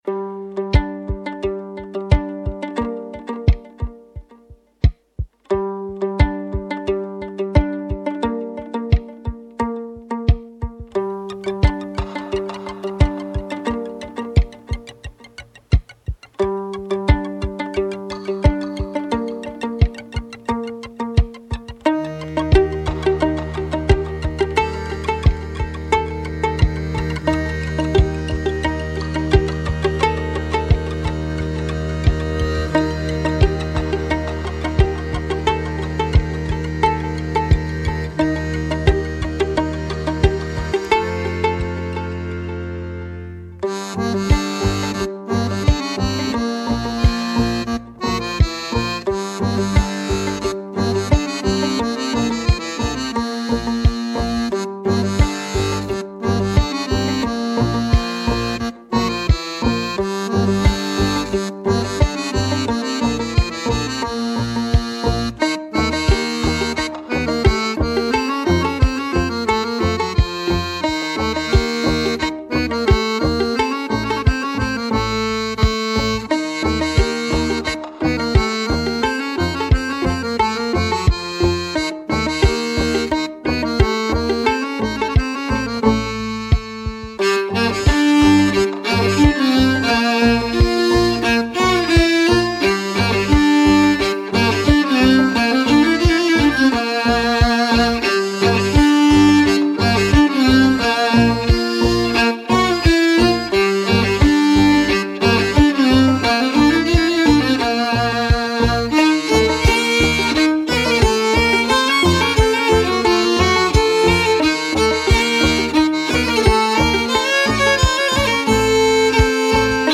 musiques traditionnelles pour le bal folk
scottish
Violon, voix, guimbarde, effets
Guitare, accordéon, voix, effets